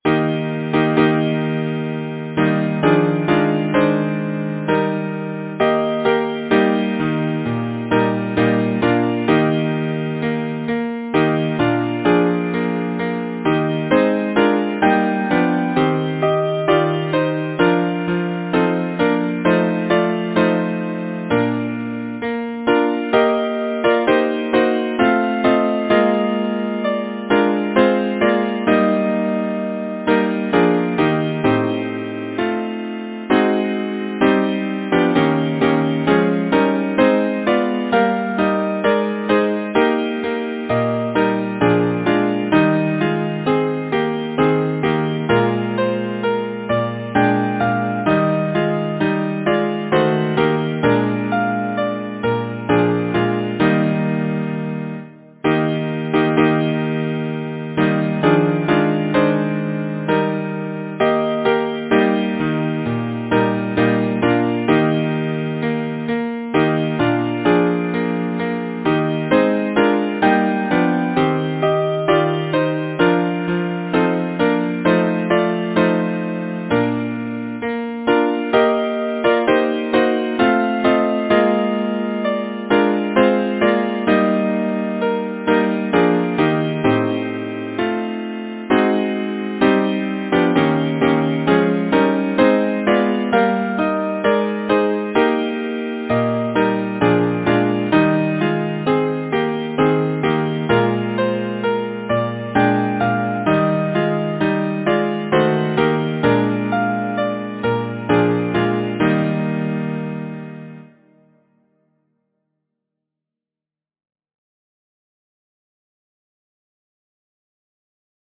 Title: Softly come! thou evening gale Composer: Henry Thomas Smart Lyricist: Frederick Enoch Number of voices: 4vv Voicing: SATB Genre: Secular, Partsong
Language: English Instruments: A cappella